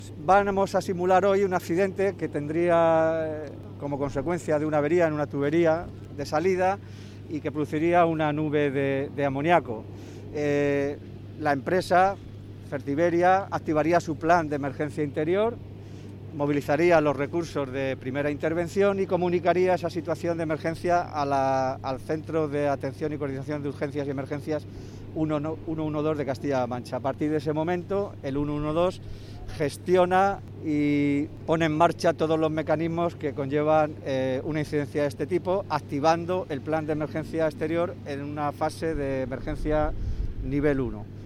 En declaraciones a los medios de comunicación, el director general de Protección Ciudadana, Emilio Puig, ha explicado que el ejercicio de simulación realizado esta mañana “forma parte de las acciones que se desarrollan en la implantación del Plan de Emergencia Exterior de Puertollano, cuyo fin es prevenir o, en caso de emergencia, mitigar las consecuencias de accidentes graves”.
audio_emilio_puig-_explicacion_del_plan.mp3